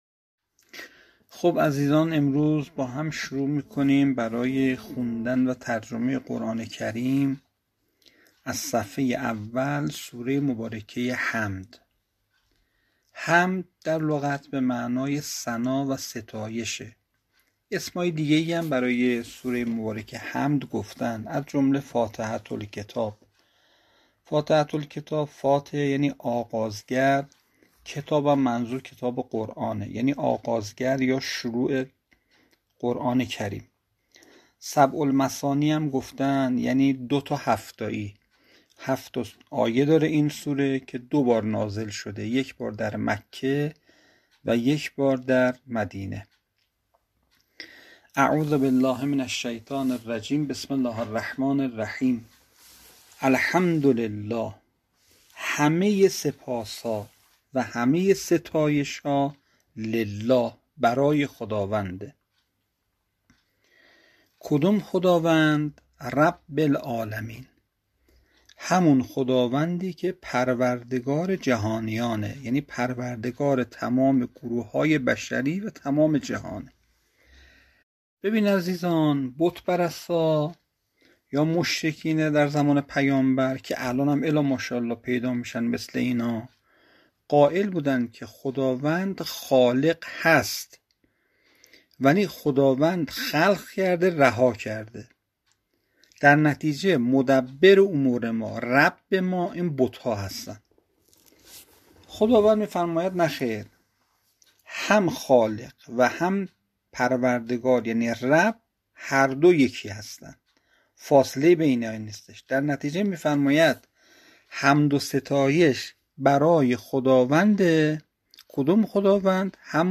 ترجمه صوتی سوره حمد(زمان 4دقیقه)